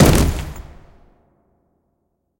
anonTheaterFall.ogg